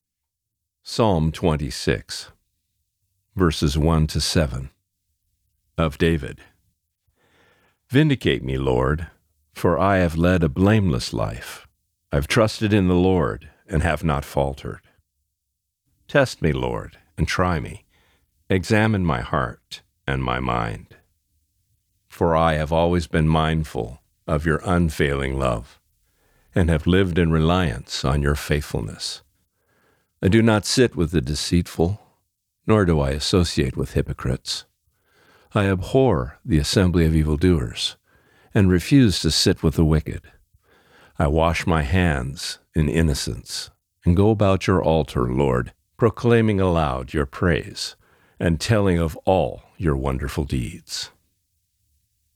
Reading: Psalm 26:1-7